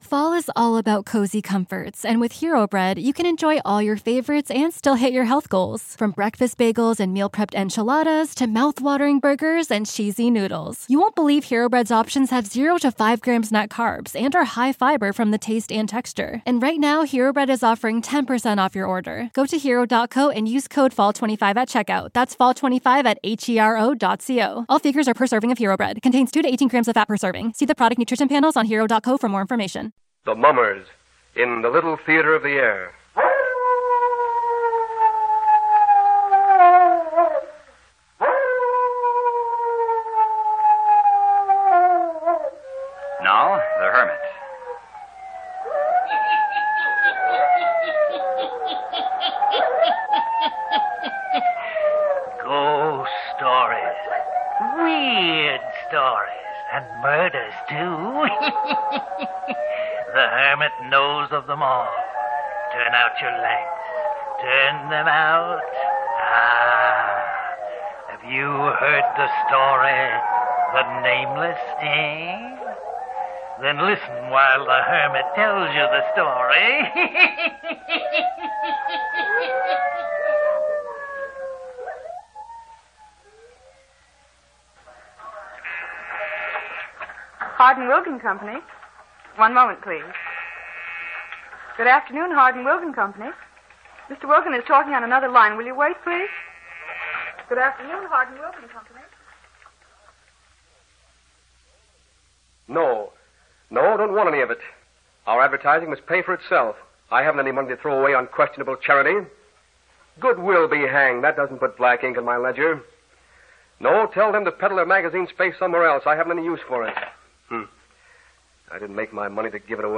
On this episode of the Old Time Radiocast we present you with two stories from the classic radio program The Hermit's Cave!